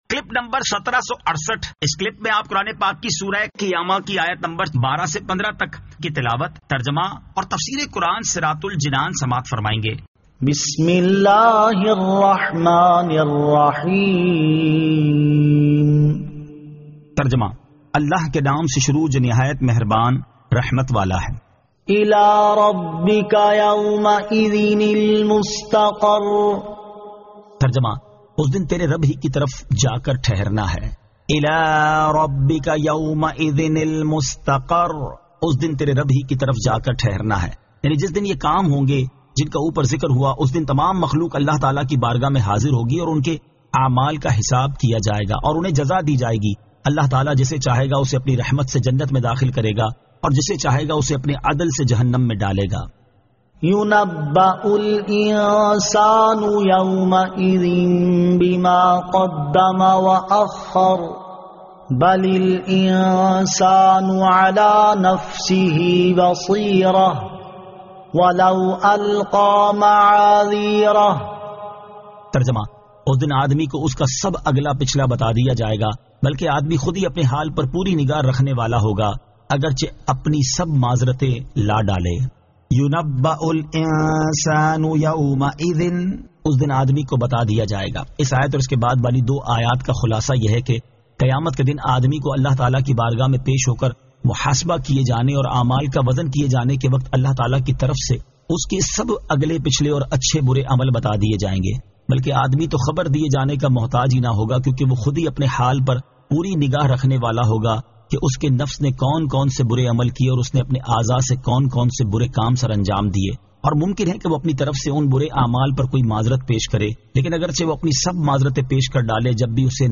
Surah Al-Qiyamah 12 To 15 Tilawat , Tarjama , Tafseer